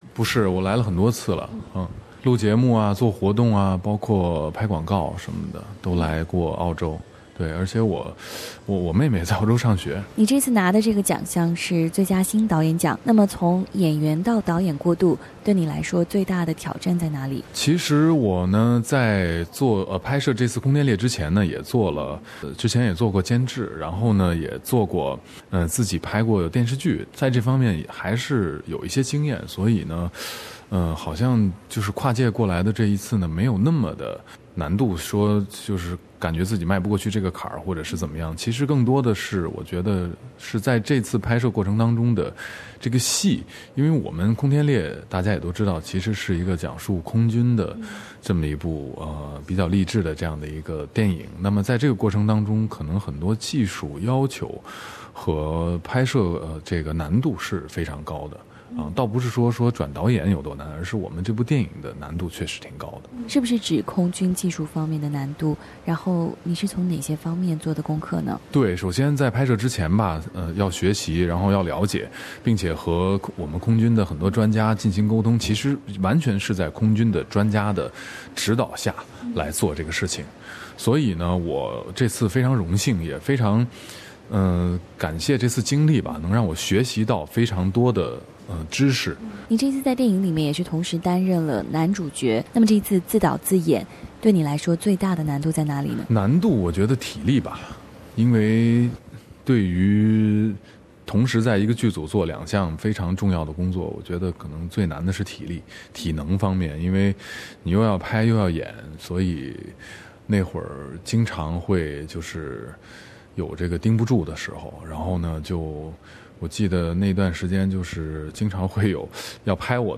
【SBS专访】李晨凭自导自演处女作《空天猎》获华语电影节最佳新导演奖